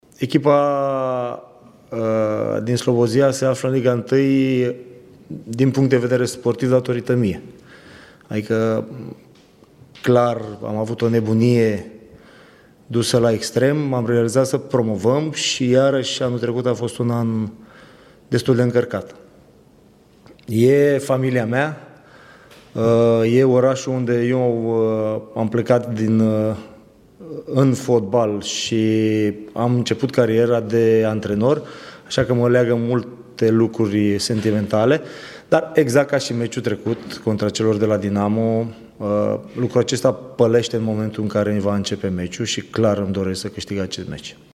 Adrian Mihalcea a declarat, în cadrul conferinței de presă care a precedat meciul de vineri nici mai mult nici mai puțin decât că pentru el Unirea Slobozia rămâne familia sa.